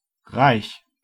Ääntäminen
Ääntäminen Tuntematon aksentti: IPA: [ʁaɪç] Haettu sana löytyi näillä lähdekielillä: saksa Käännös Adjektiivit 1. rikas Muut/tuntemattomat 2. varakas 3. vauras 4. äveriäs Esimerkit Sie ist reich .